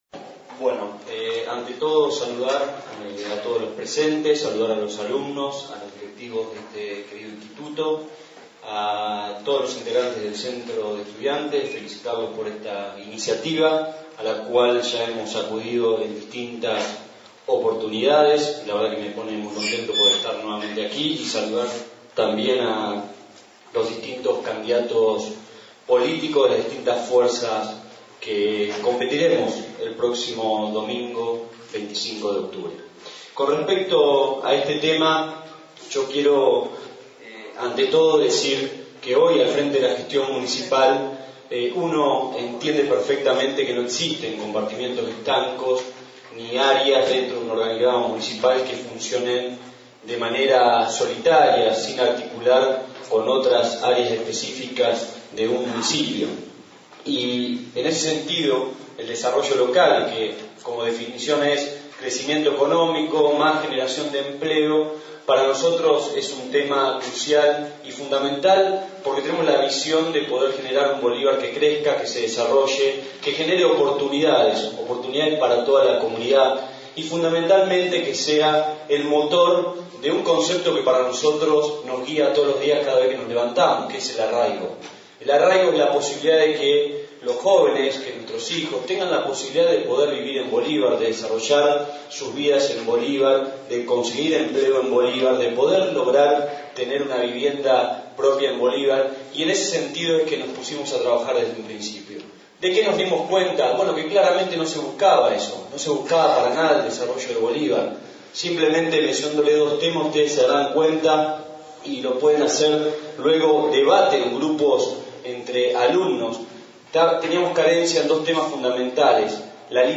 DEBATE DE CANDIDATOS A INTENDENTE 2015 DEL ISFD y T Nº27 :: Radio Federal Bolívar
AUDIO - Tiene algunos cortes por fallas de Movistar